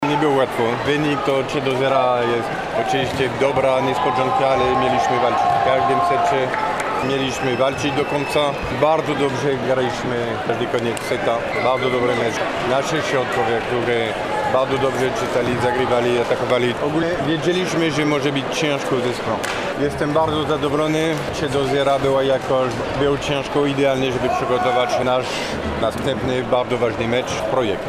– analizował szkoleniowiec gospodarzy, Stephane Antiga.